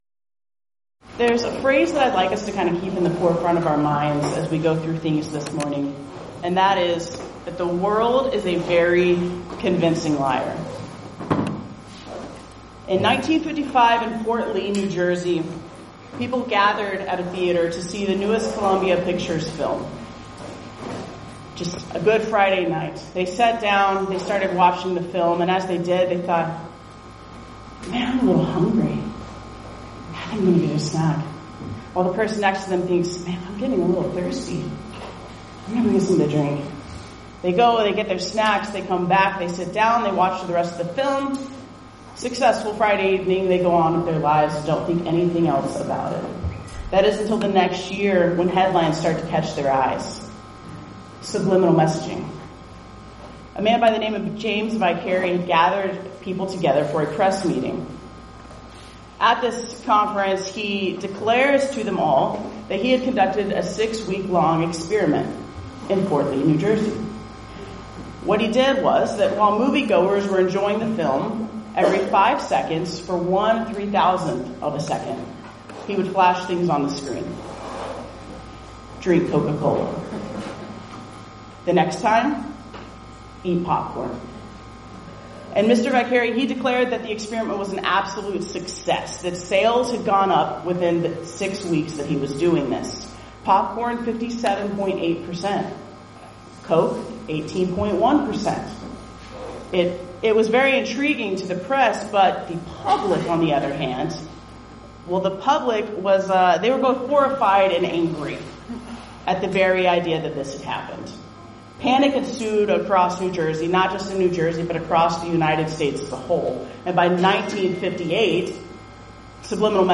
Event: 8th Annual Women of Valor Ladies Retreat
lecture